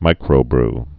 (mīkrō-br)